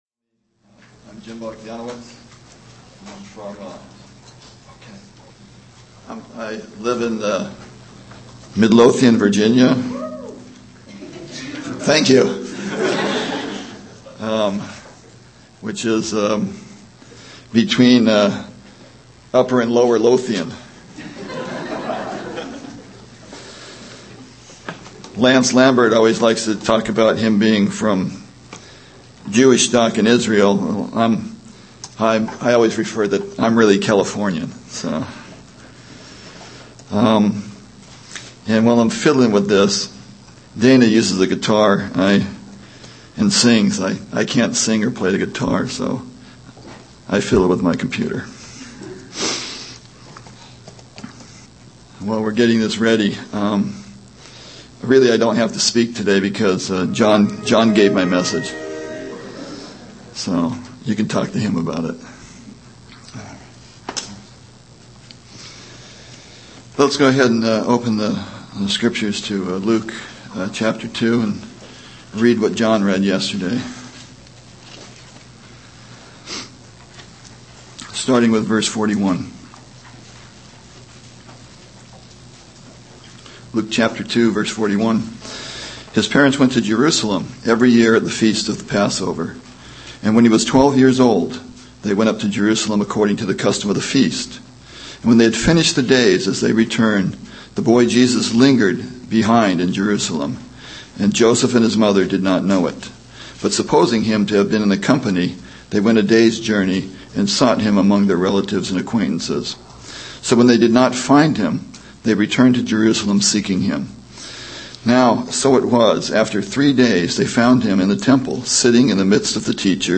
A collection of Christ focused messages published by the Christian Testimony Ministry in Richmond, VA.
Toronto Summer Youth Conference